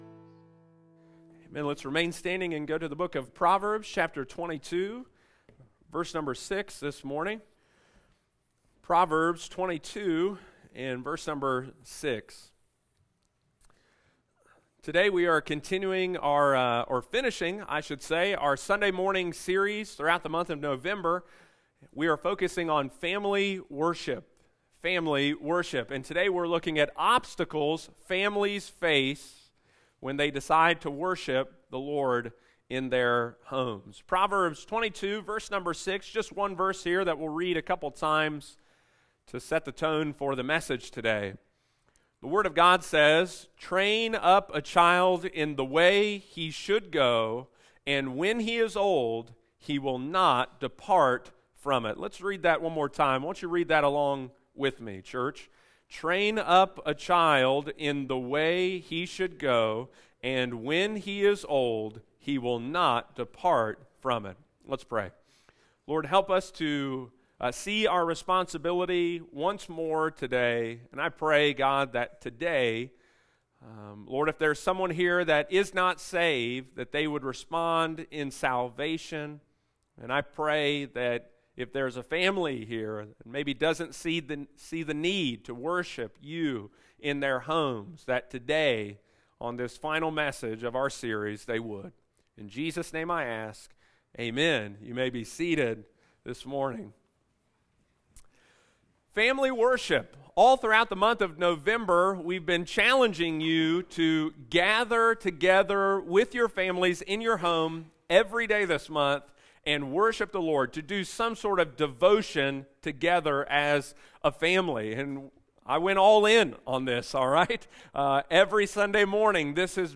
Sunday morning, November 27, 2022.